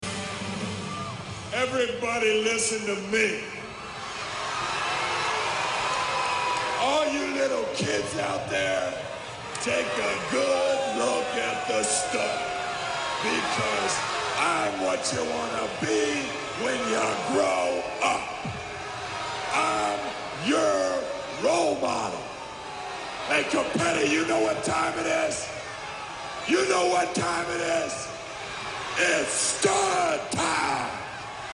The one thing I am very sad that was dropped in his transition to being Razor was this here promo here